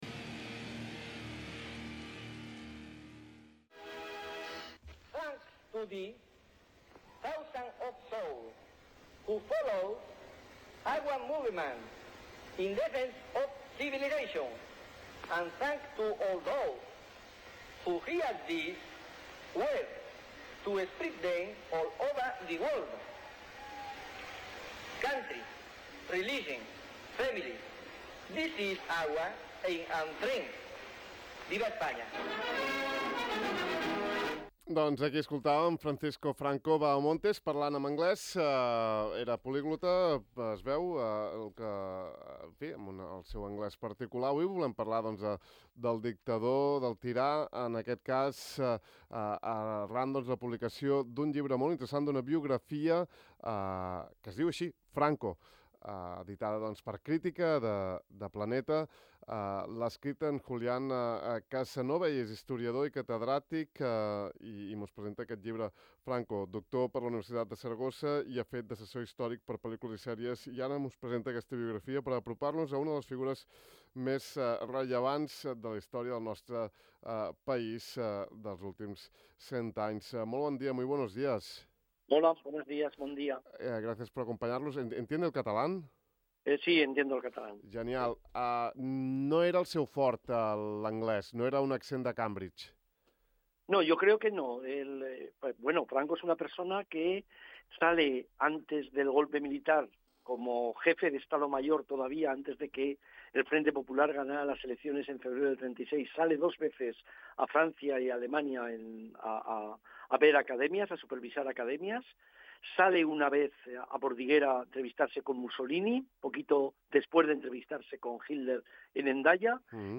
Enguany marcarà el cinquanta aniversari de la mort del dictador Francisco Franco. Al De far a far hem entrevistat al catedràtic d’història Julián Casanova, que acaba de publicar la seva biografia de Franco al segell Crítica de Planeta.